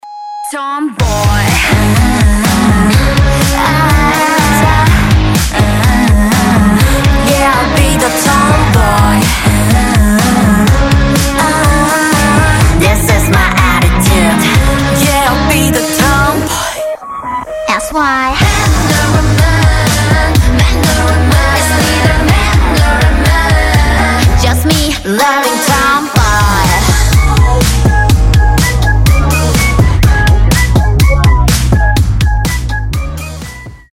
свист
озорные
корейские
K-Pop